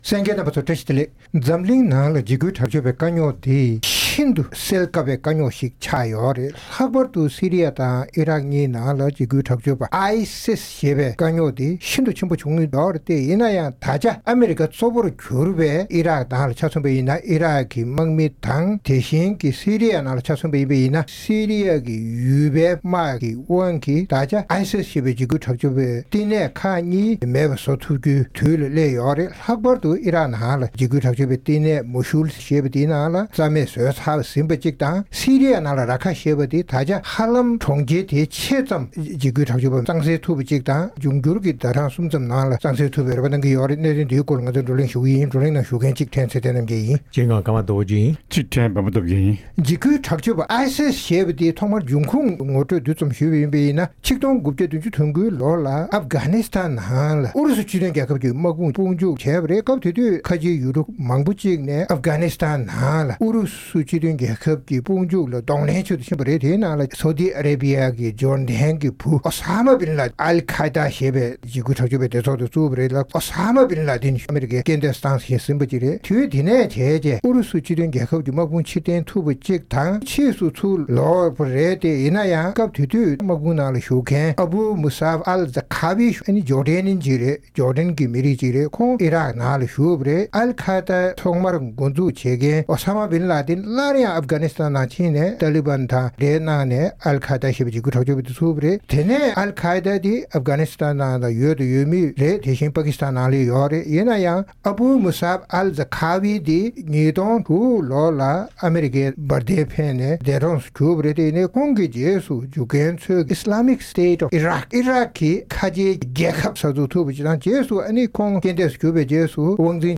༄༅། །རྩོམ་སྒྲིག་པའི་གླེང་སྟེགས་ཞེས་པའི་ལེ་ཚན་ནང་། འཇིགས་སྐུལ་དྲག་སྤྱོད་ཀྱི་ཚོགས་པ་ISIS ཞེས་པ་དར་ཁྱབ་བྱུང་སྟངས་དང་། ཨ་རི་དང་མཐུན་ཕྱོགས་ཡུལ་གྲུས་ཕར་རྒོལ་གྱིས་Iraq གི་Mosul ནས་ཚར་བཅད་ཟིན་པ་དང་། Syria ཡི་Raqqa གྲོང་ཁྱེར་ཕྱེད་ཙམ་དབང་བསྒྱུར་ཟིན་ཏེ་རིང་མིན་ཆ་ཚང་ལ་དབང་སྒྱུར་ཡོང་ངེས་པ་སོགས་ཀྱི་སྐོར་རྩོམ་སྒྲིག་པ་རྣམ་པས་བགྲོ་གླེང་གནང་བ་ཞིག་གསན་རོགས་གནང་།